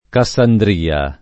vai all'elenco alfabetico delle voci ingrandisci il carattere 100% rimpicciolisci il carattere stampa invia tramite posta elettronica codividi su Facebook Cassandria [ ka SS andr & a ; alla greca ka SS# ndr L a ] o Cassandrea [ ka SS andr $ a ] top. stor. (Gr.)